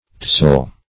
Tousle \Tou"sle\, v. t. [Freq. of touse.